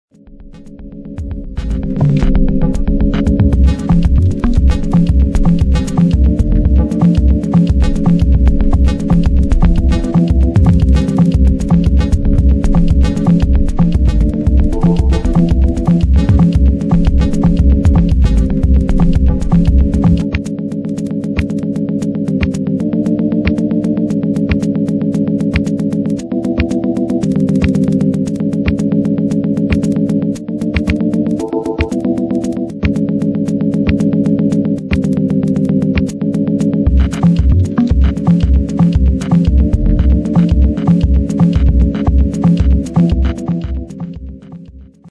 subtle, textured music